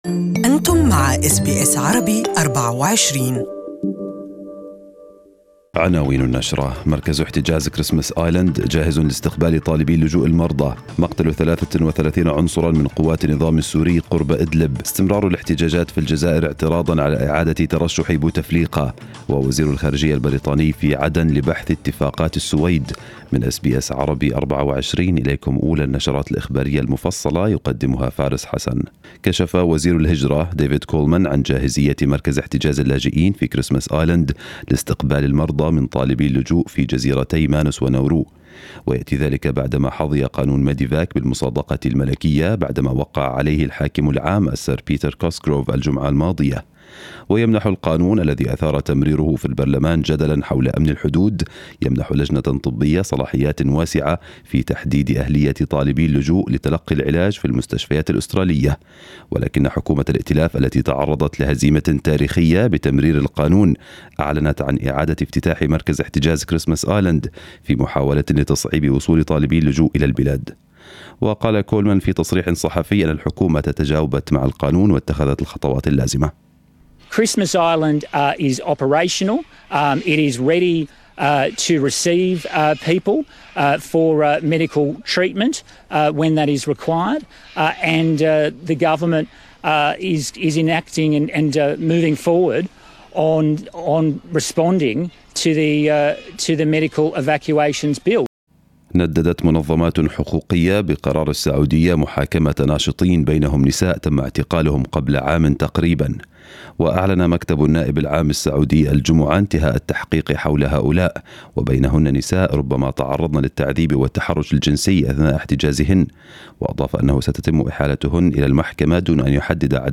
Arabic news bulletin from SBS Arabic24.